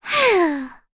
Cecilia-Vox_Sigh.wav